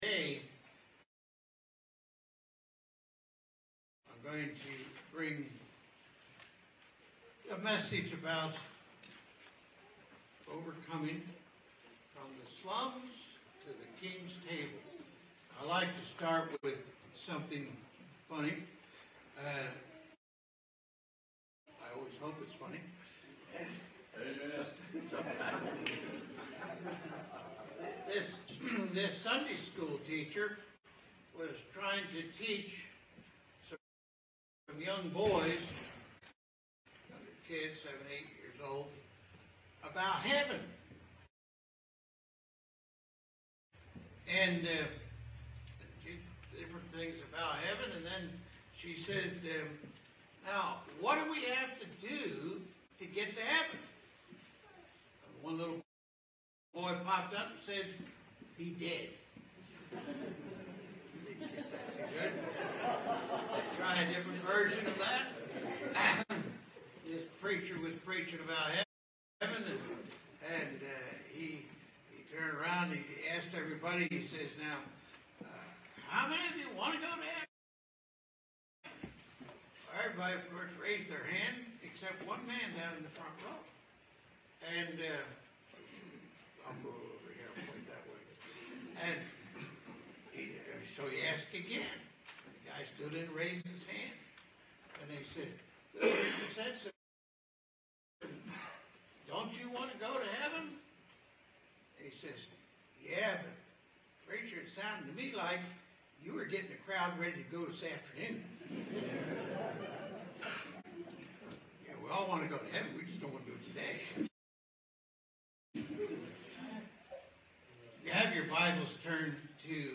Sermon-11-9-21-audio-CD.mp3